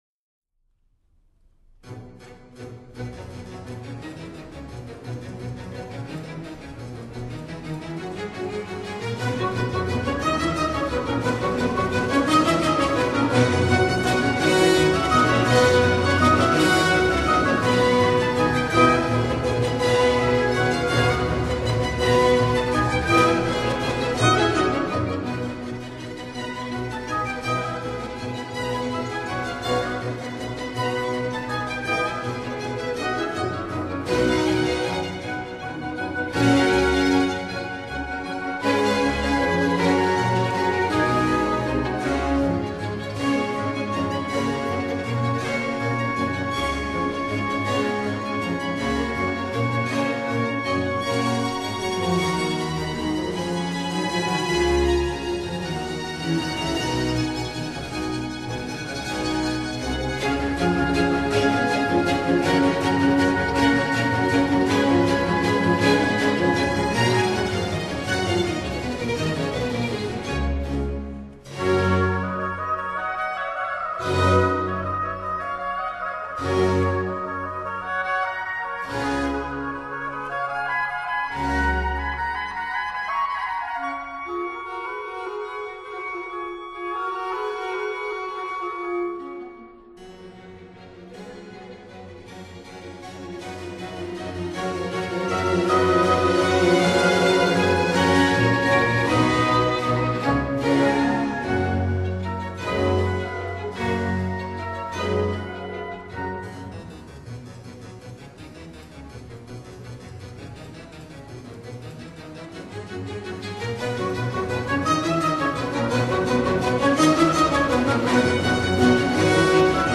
這張專輯的音質有一種非常迷人的甜美澄澈味道，只要音響系統夠好，肯定你能夠體會到他們錄音優越、美質的特性。